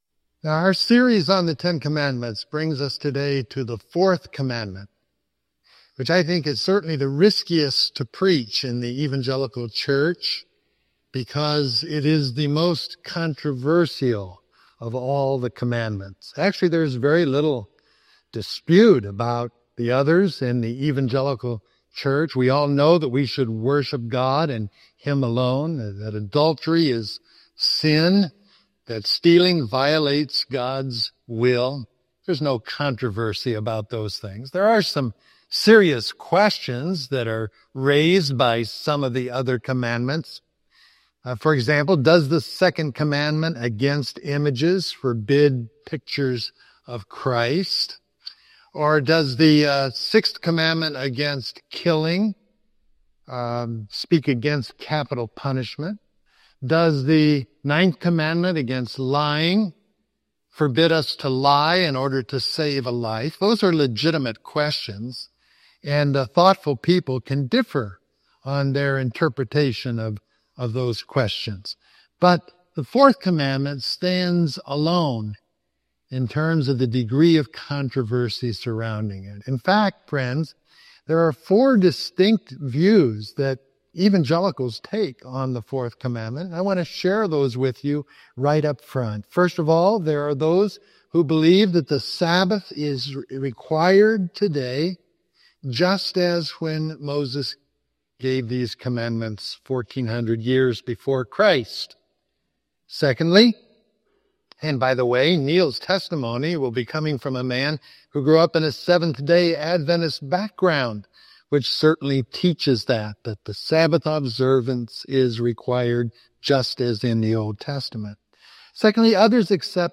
Introduction: Our series on the Ten Commandments brings us today to the fourth commandment. The fourth commandment may be the riskiest one to preach in the evangelical church; certainly, it is the most controversial.